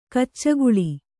♪ kaccaguḷi